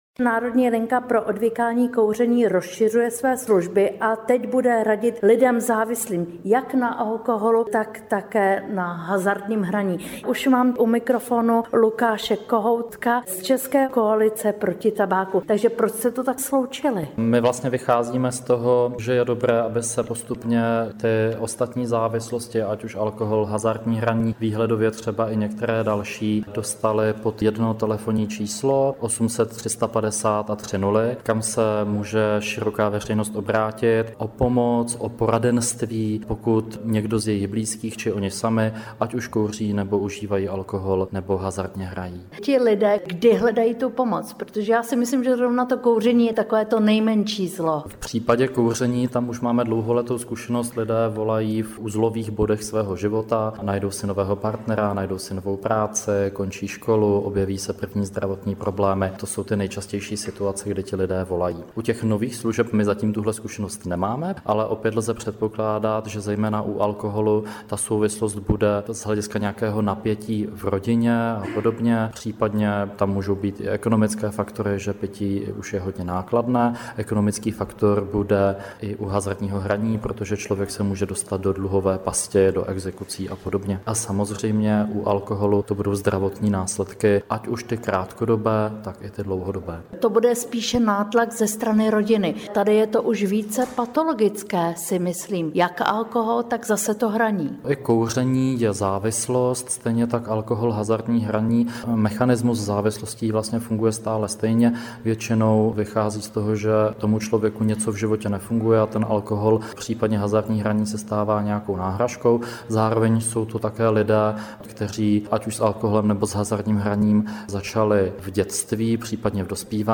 AUDIO rozhovor: Co více jsme o závislostech zjistili?